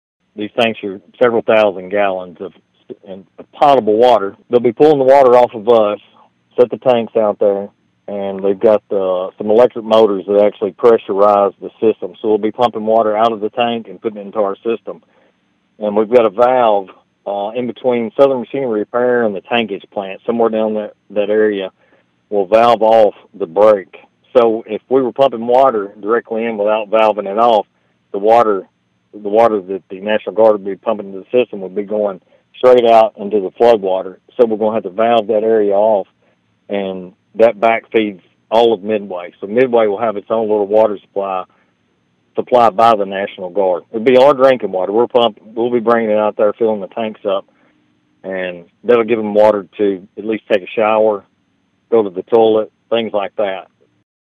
The City Manager explained how the National Guard tanks could help in providing water back to the community until a line repair can be done.(AUDIO)